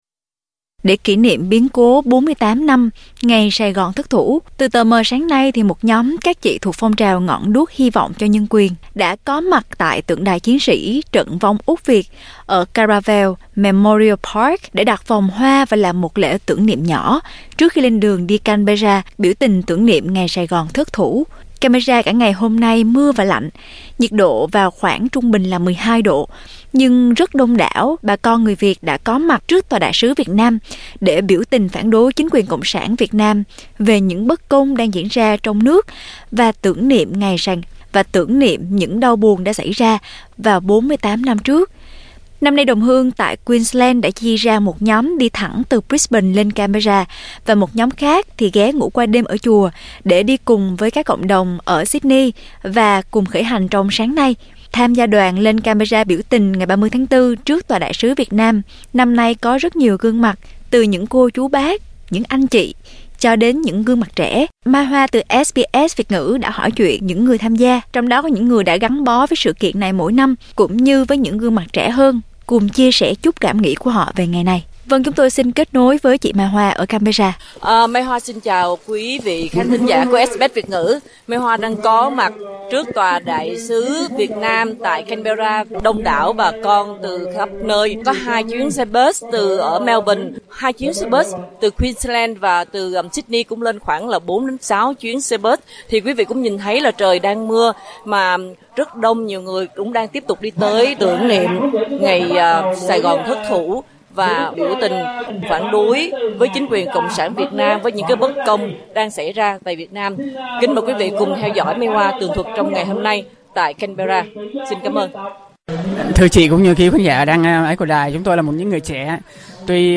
Tường thuật sự kiện tưởng niệm ngày 30/4 tại Canberra